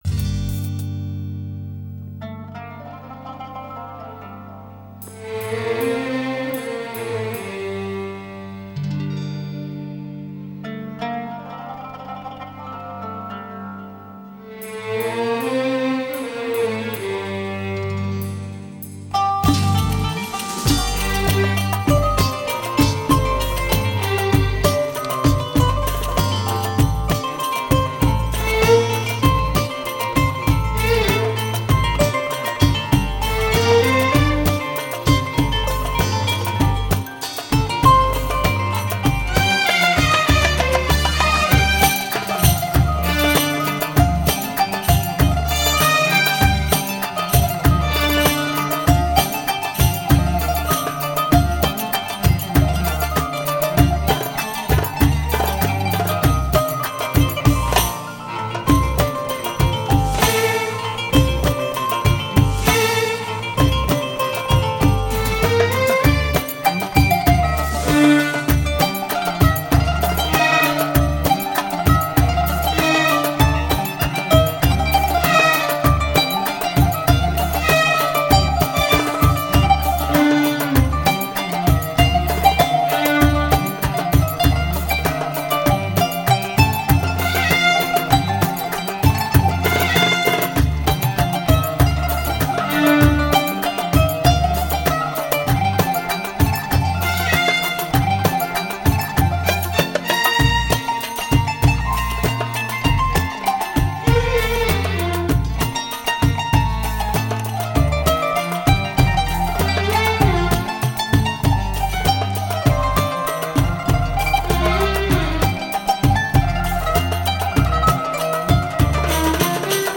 Genre: World.